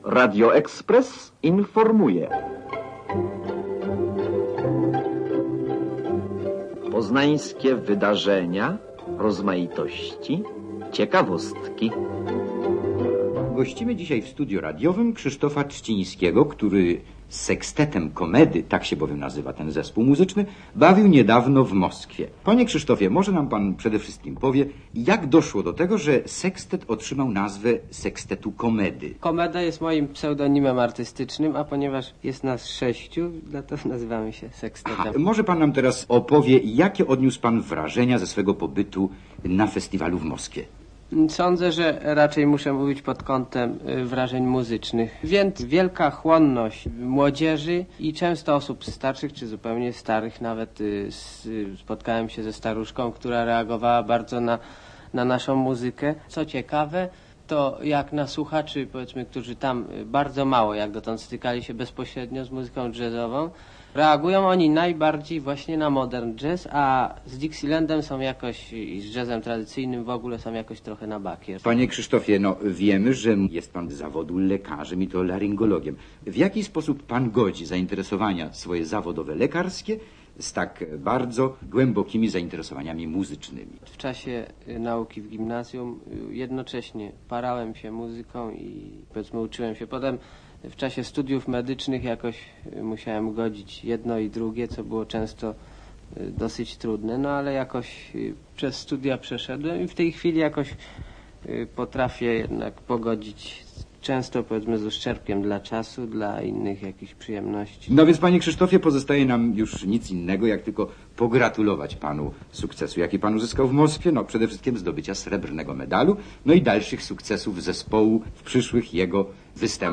0hmyat002jor1gl_komeda_reportaz.mp3